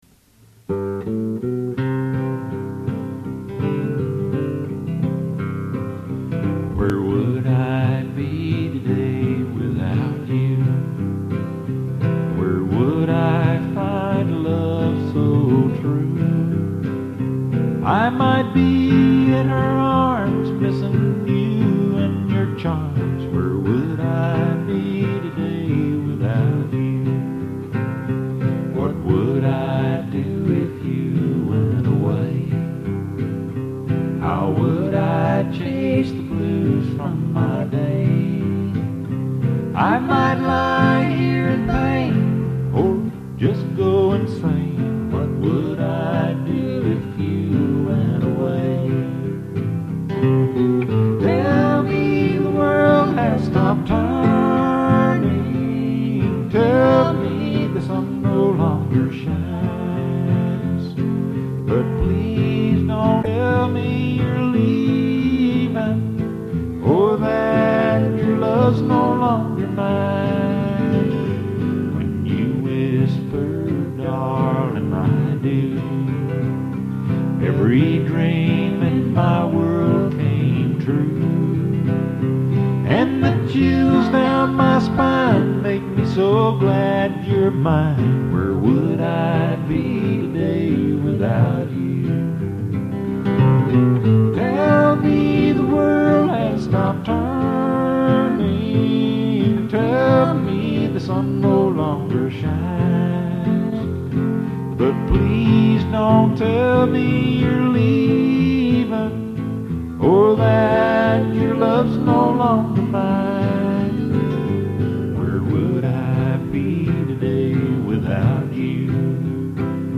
CASSETTE DEMO